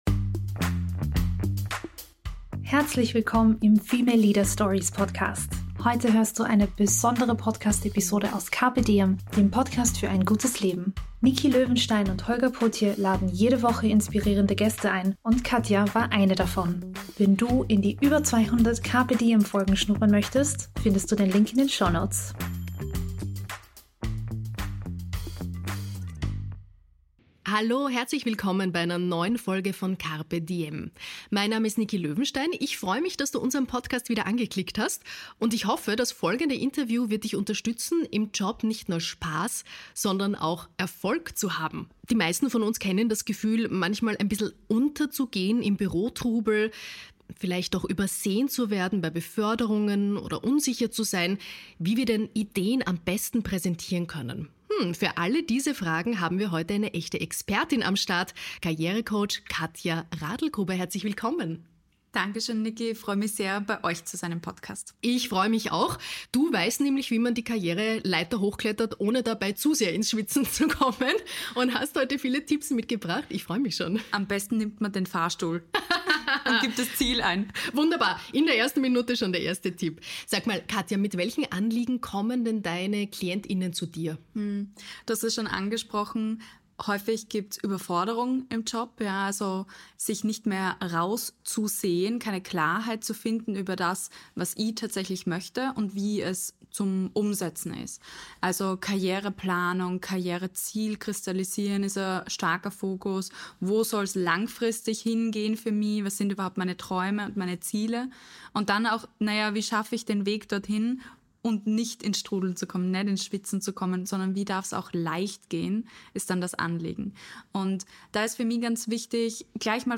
Interview im Carpe Diem Podcast